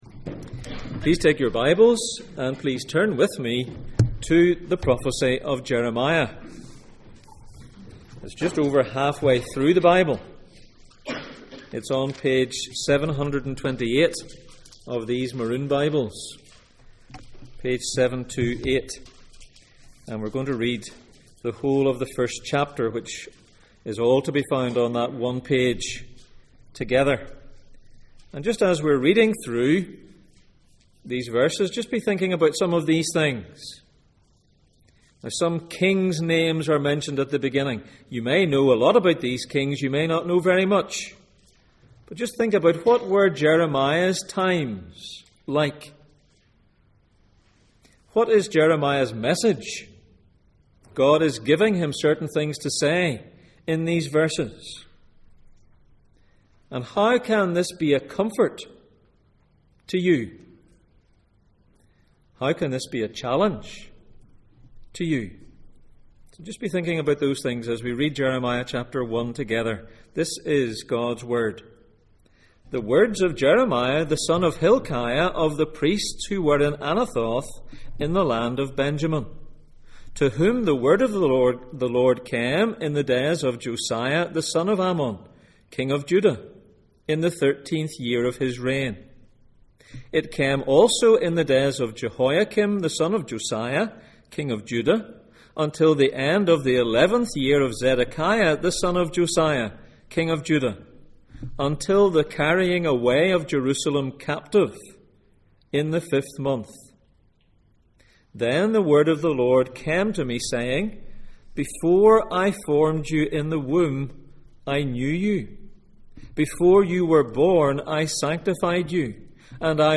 The highs and lows of Jeremiah Passage: Jeremiah 1:1-19, Matthew 5:11-12, Jeremiah 6:14 Service Type: Sunday Morning